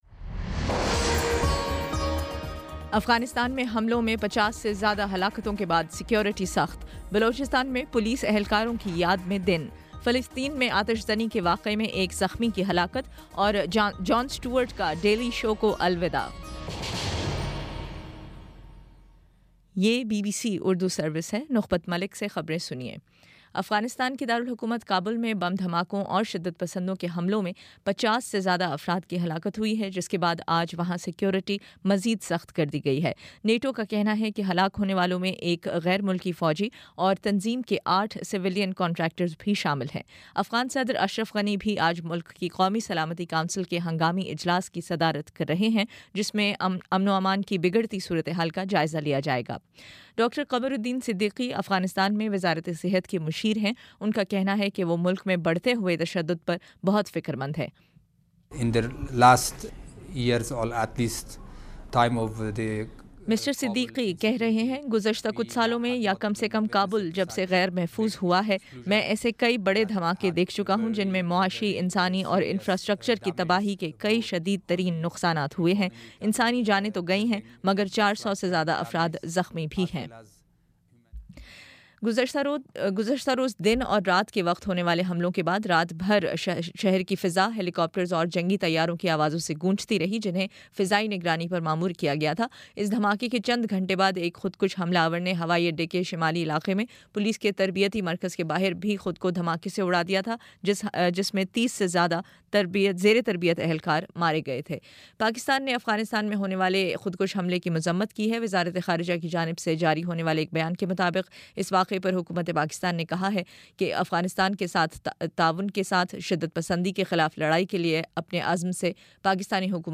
اگست 08: شام پانچ بجے کا نیوز بُلیٹن